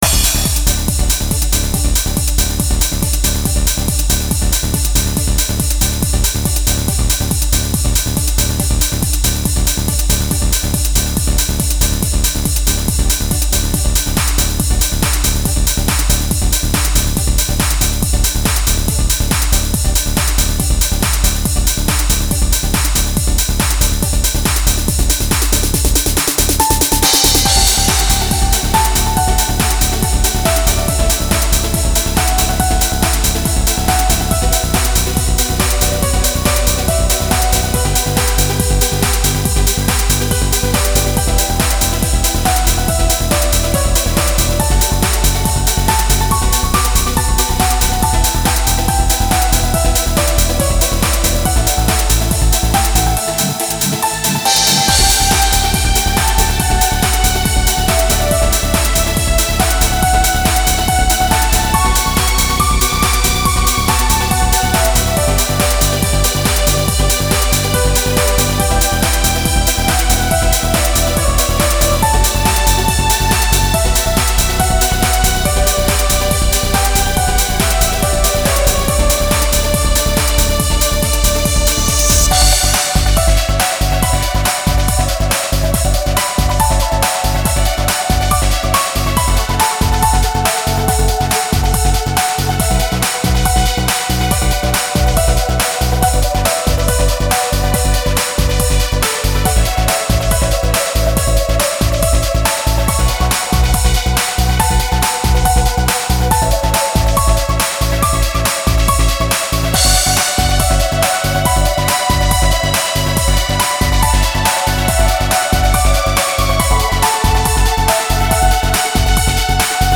いわゆる同人トランスです。